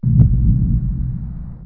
Jurassic Park: T-Rex step - far away